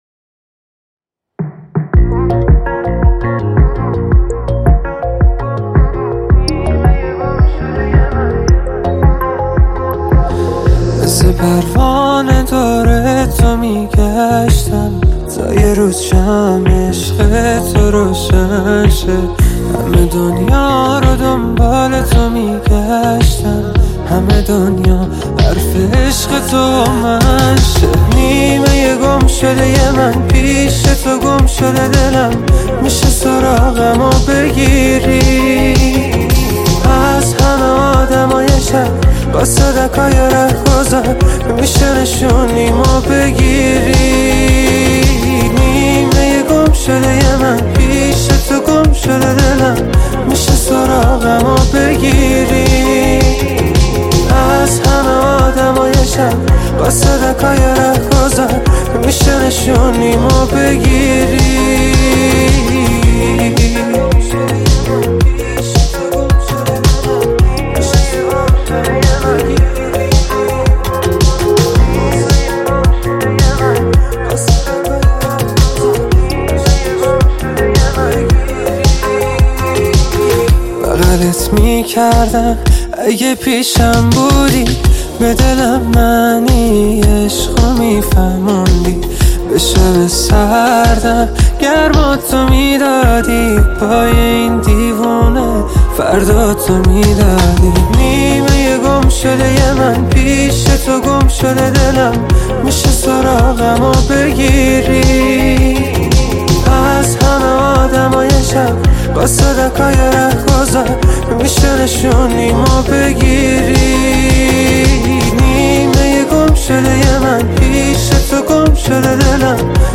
عاشقانه و غمگین